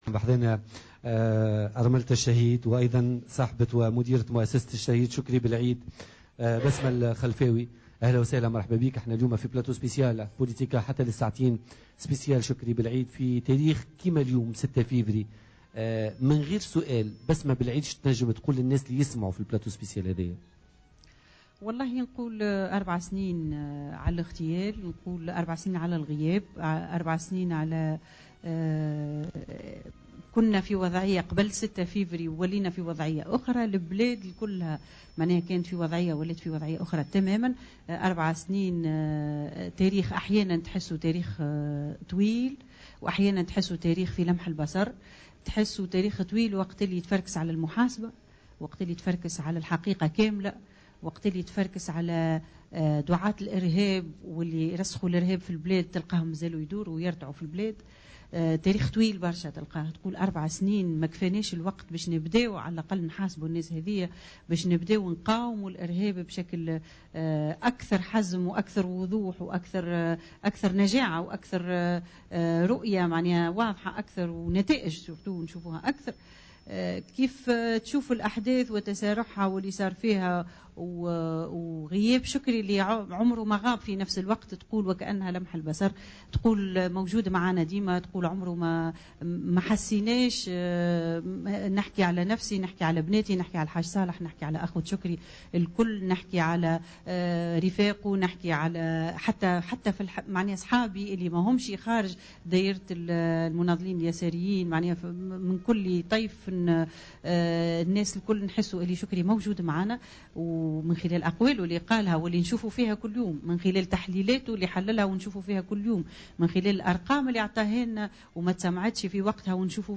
وأضافت ضيفة "بوليتكا" اليوم مباشرة من جبل جلود بمناسبة إحياء ذكرى اغتيال بالعيد،" 4 سنوات تاريخ طويل عند البحث عن المحاسبة والحقيقة كاملة خاصة وأننا نرى دعاة الإرهاب يرتعون في البلاد..